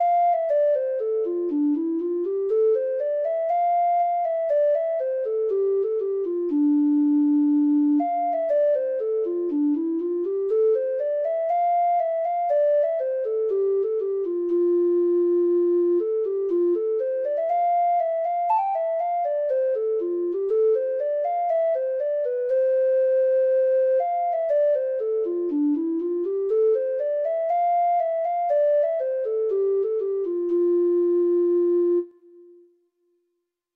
Traditional Trad. MY OWN SWEETHEART (Irish Folk Song) (Ireland) Treble Clef Instrument version
Irish